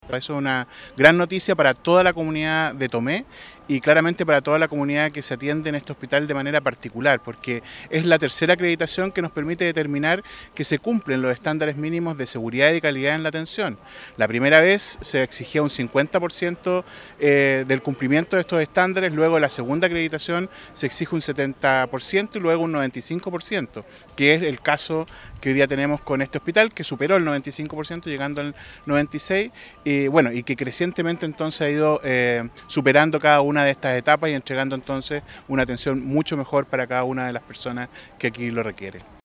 El superintendente de Salud, Víctor Torres Jeldes, también destacó la importancia de este hito para la comuna y se refirió a las dificultades del proceso de acreditación.
Victor-Torres-Superintendente-de-Salud.mp3